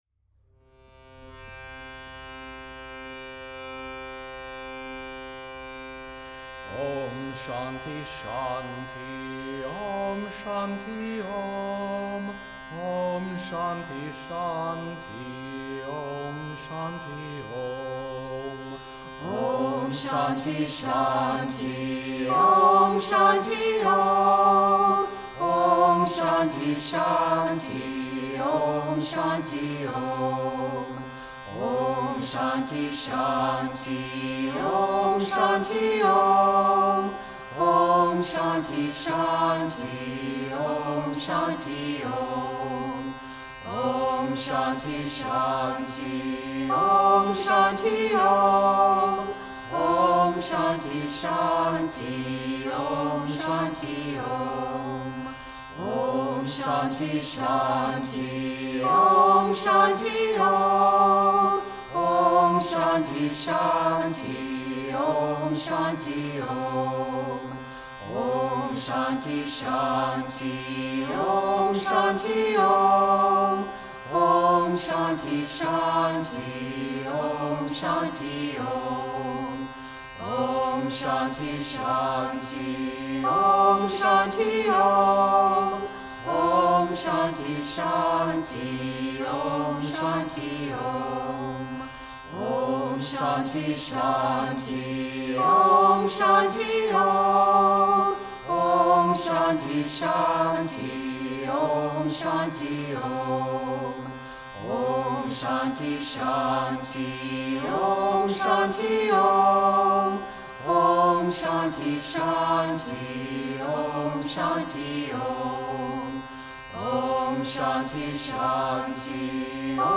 Interfaith Chants for Meditation